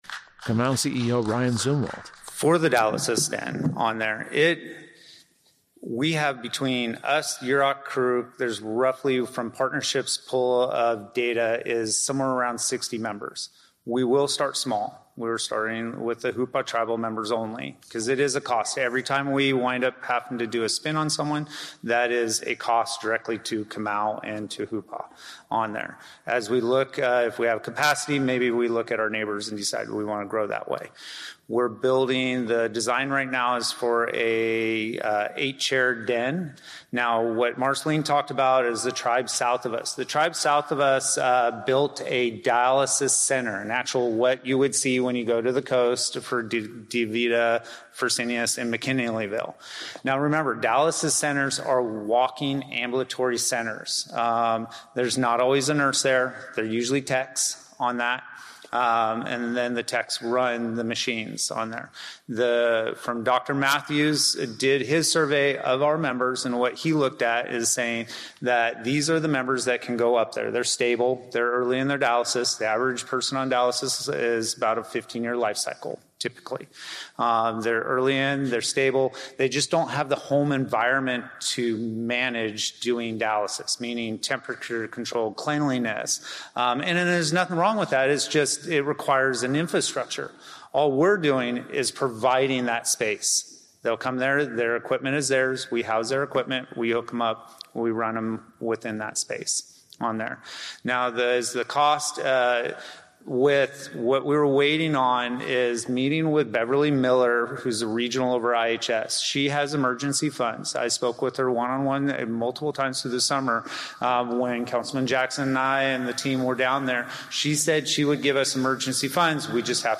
This is the 5th hour of the 6-hour recording of the Hoopa Valley General Meeting of 2-21-26.